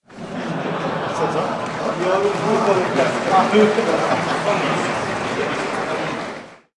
Funny VOICES and LAUGHTERS by various people » 00712 massive men and old man laughter
描述：massive men laugh with close old man laughter rec by Zoom H2
标签： laugh laughter man massive old
声道立体声